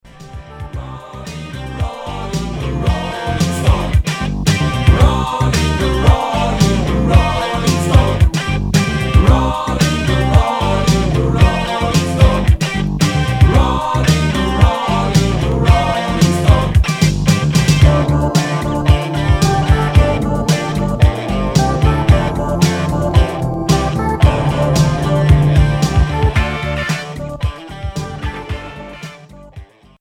(instrumental)
Rock